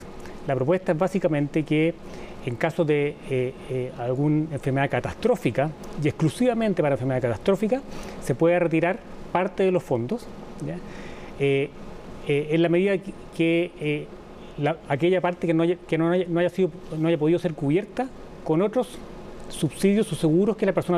La Constitución es bastante clara en el sentido de que las cotizaciones obligatorias pueden destinarse a un único fin que es la seguridad social y la seguridad social se paga por la vía de pensiones”, dijo en entrevista con CNN Chile.